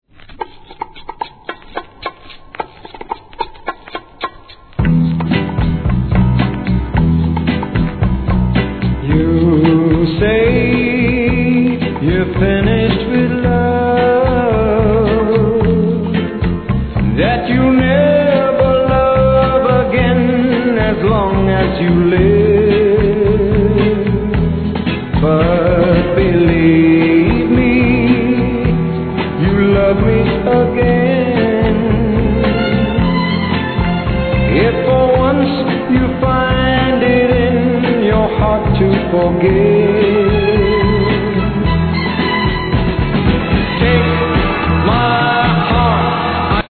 1. SOUL/FUNK/etc...
怒渋ベースのイントロにグッと来ます!!